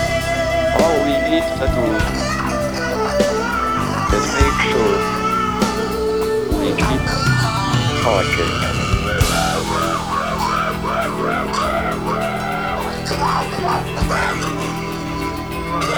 sounds like a speech replacement device.